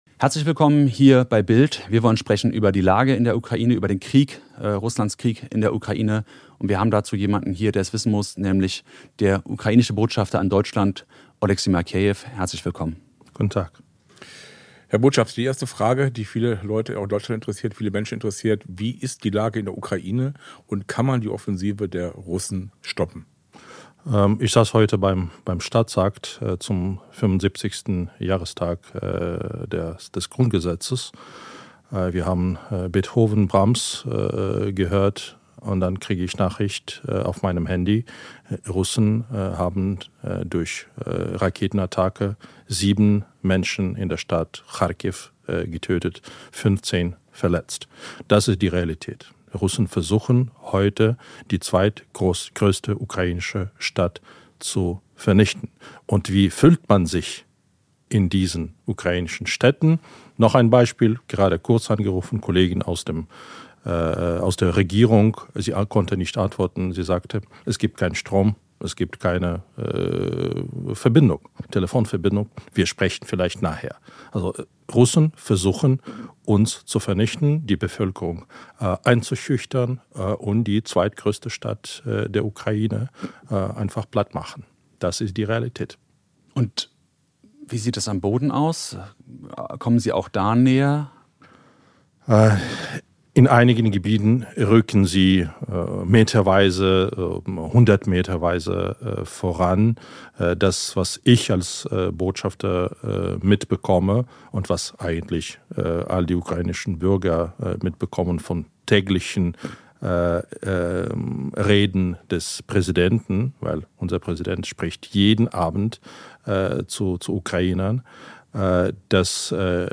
Über den aktuellen Stand des Krieges, die stockende deutsche Unterstützung und einen gewieften Plan, 300 Milliarden Euro aus russischen Vermögen in die Ukraine zu bekommen sprach BILD mit Botschafter Oleksii Makeiev (48).